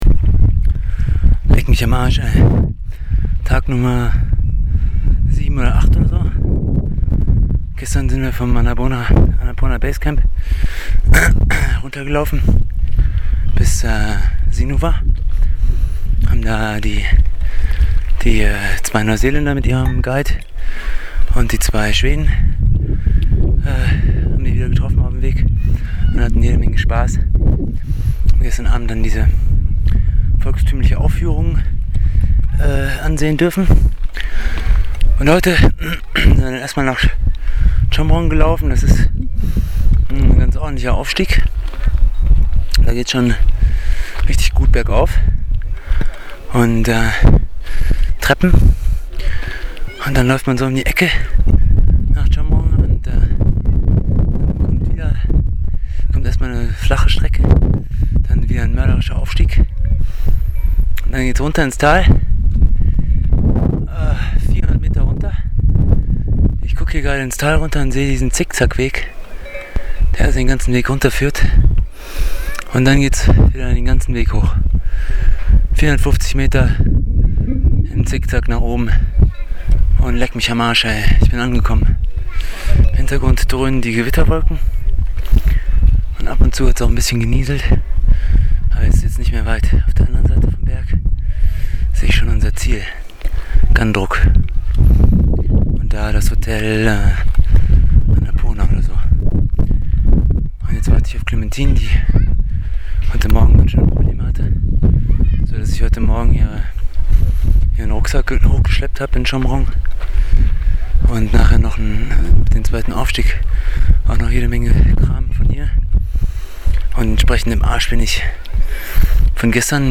Trek Tag 7 – Nepali singing 4